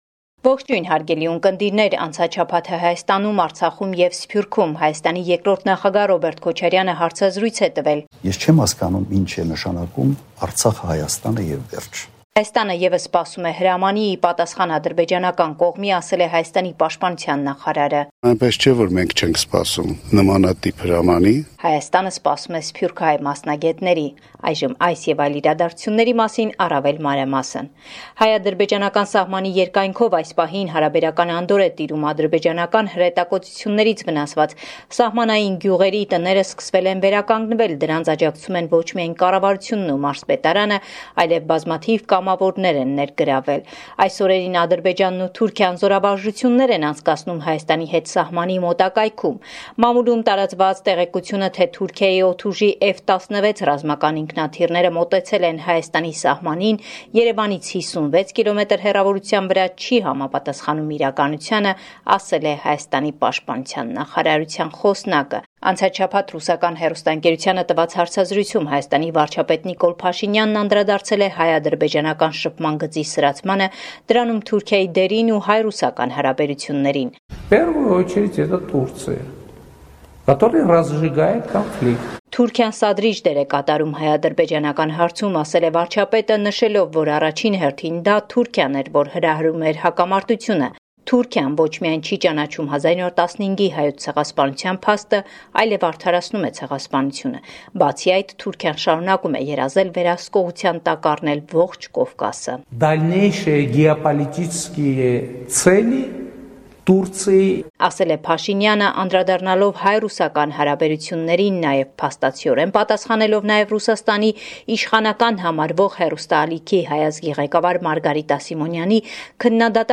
Latest News from Armenia – 4 August 2020